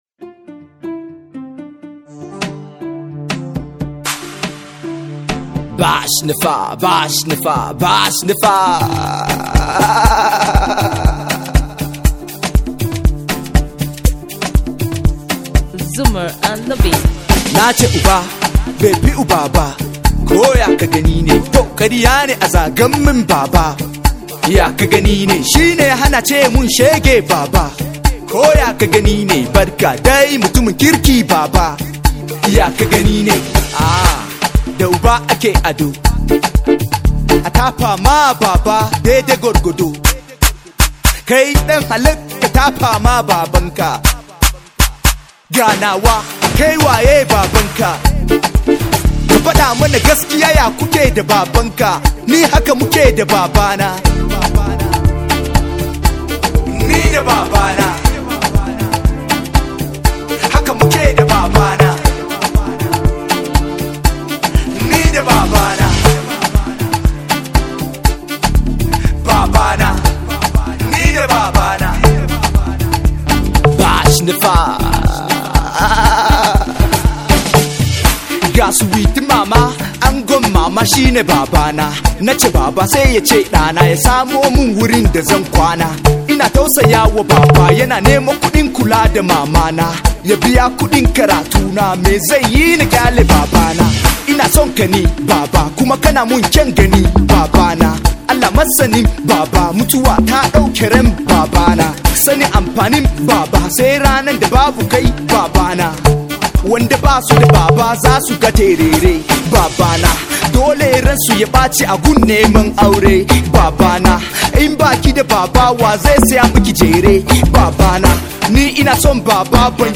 Hausa Hip Hop